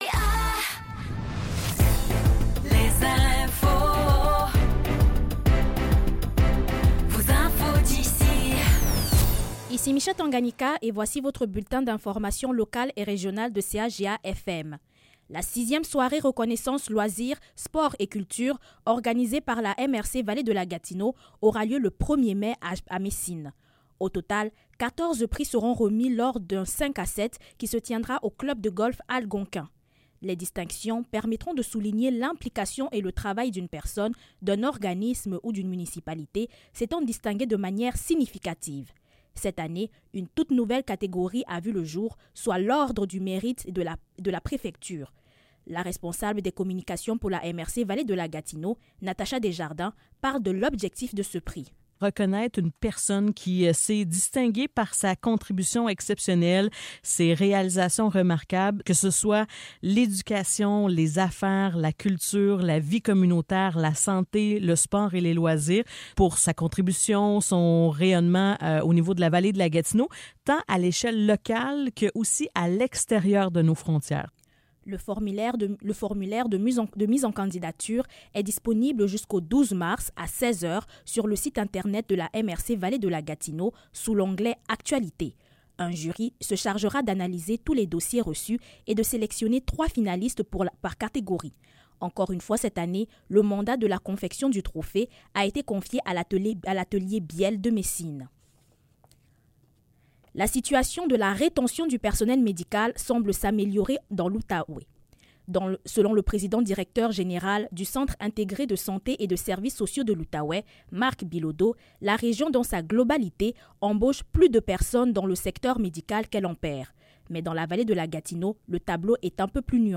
Nouvelles locales - 7 janvier 2025 - 15 h